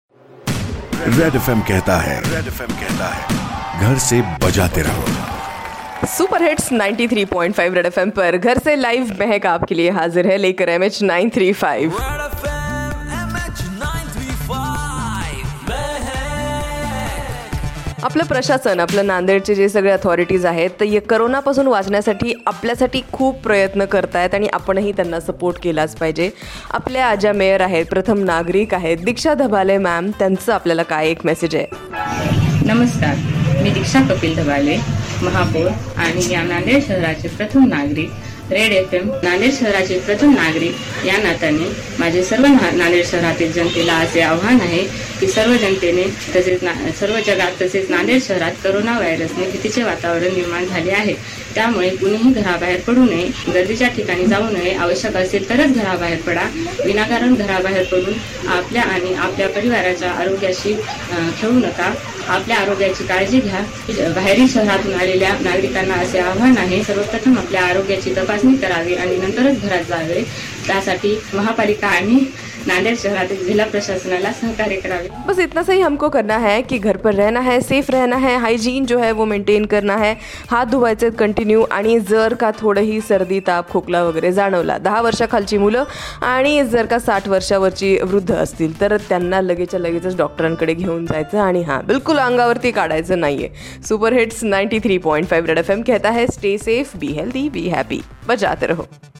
authorities bytes at the start of lockdown